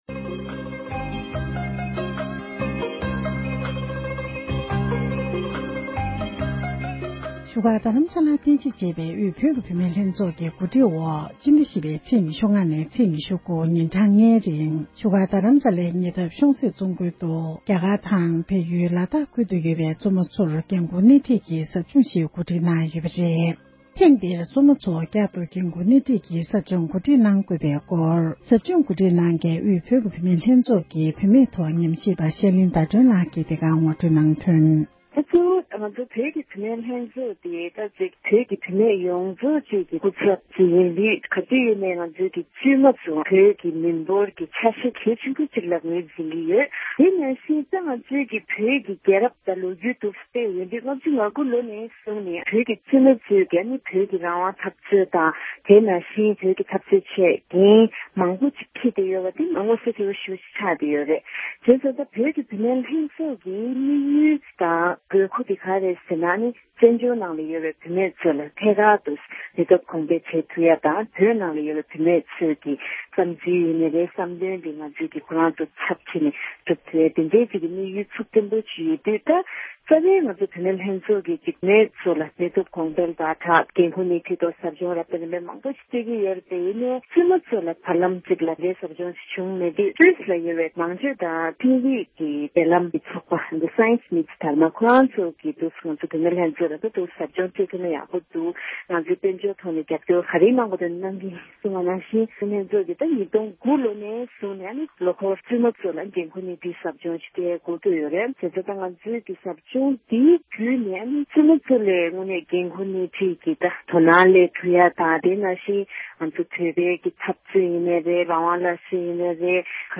འབྲེལ་ཡོད་མི་སྣར་གནས་འདྲི་ཞུས་པ་ཞིག